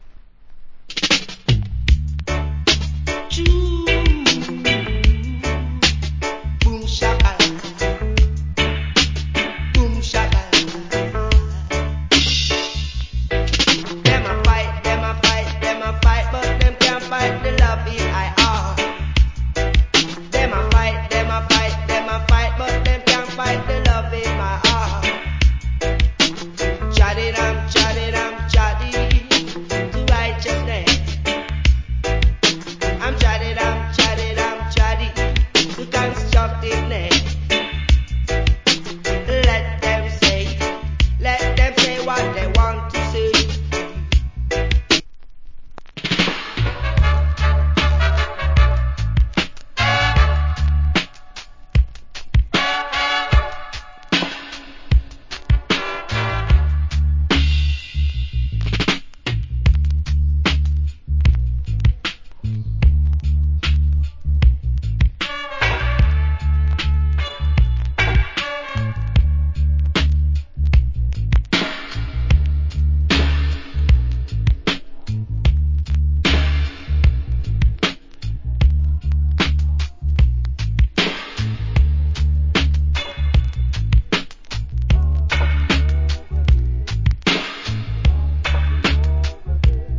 Early 80's. Nice Reggae Vocal.